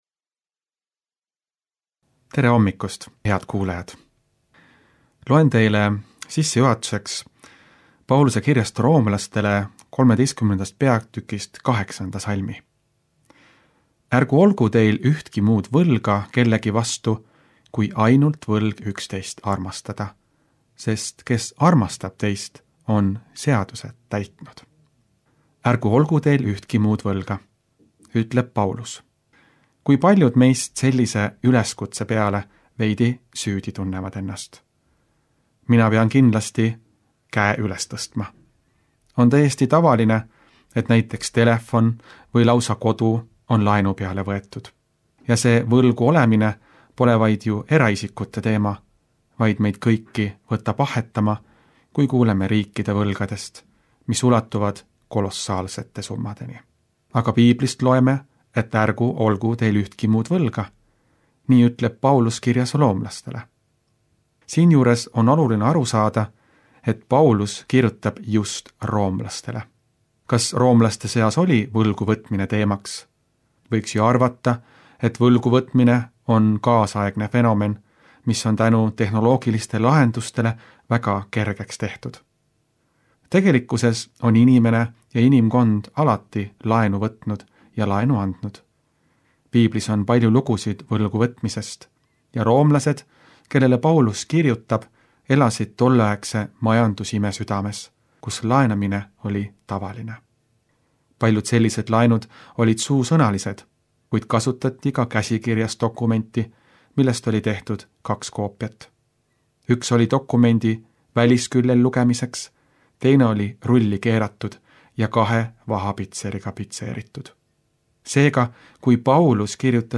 Hommikupalvused